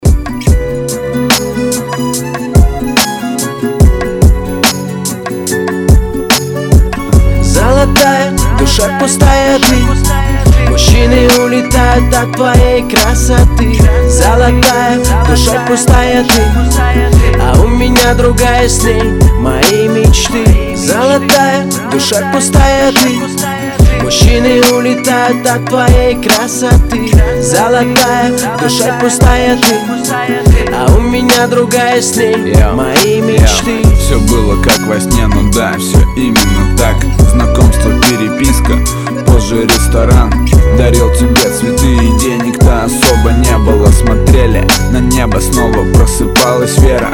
• Качество: 320, Stereo
лирика
душевные
русский рэп
красивая мелодия